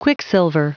Prononciation du mot quicksilver en anglais (fichier audio)
Prononciation du mot : quicksilver